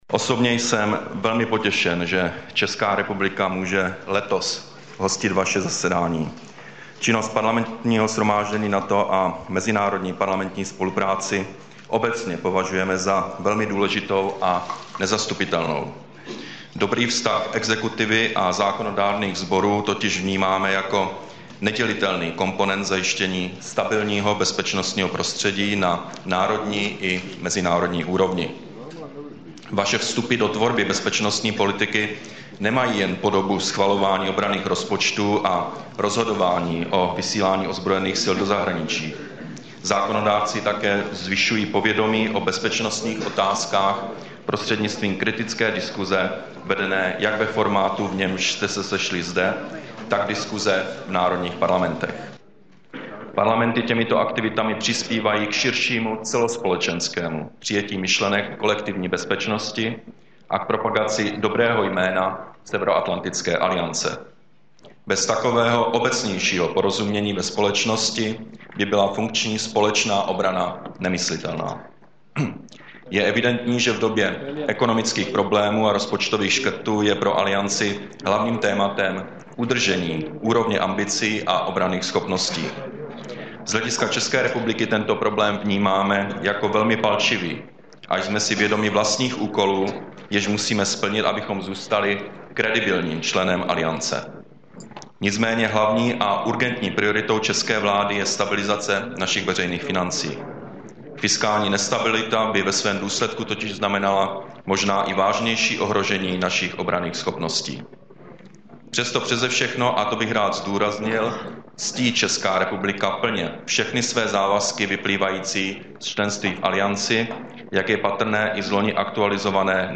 Projev předsedy vlády ČR Petra Nečase před Parlamentním shromážděním NATO, Praha 12. listopadu 2012